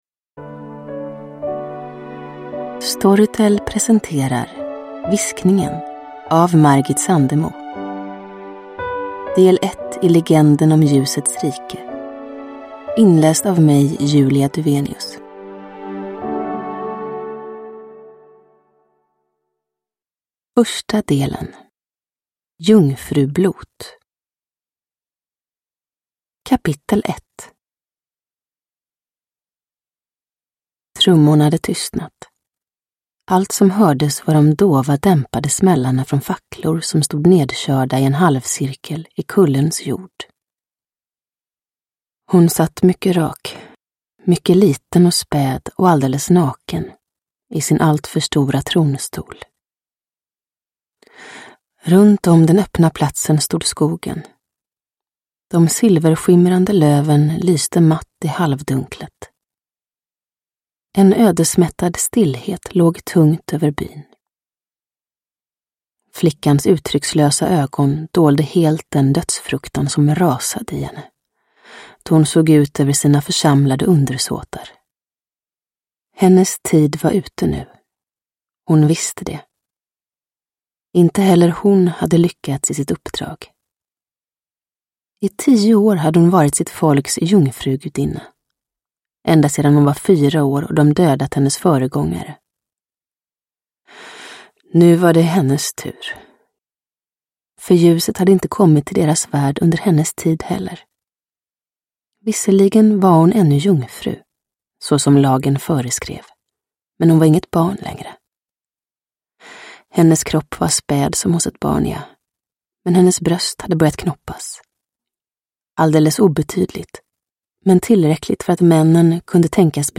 Viskningen – Ljudbok
Uppläsare: Julia Dufvenius